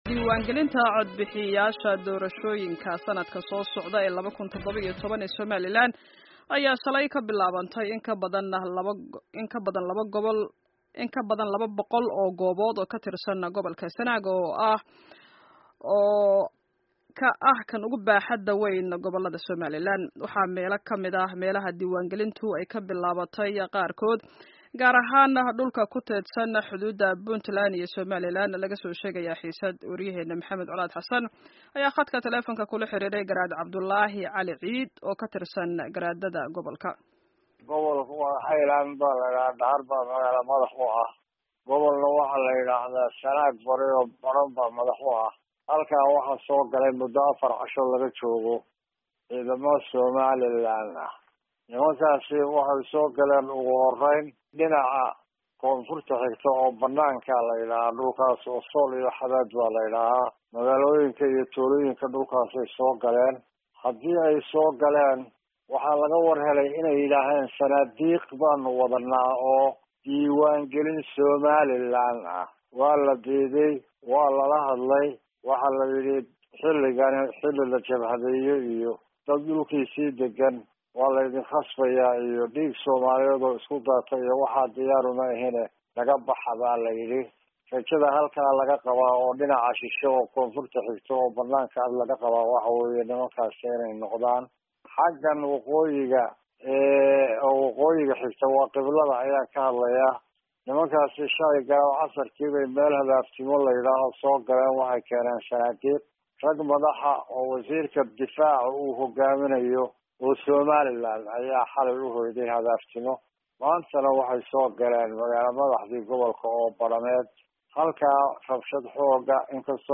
Dhageyso wareysiyada Sanaag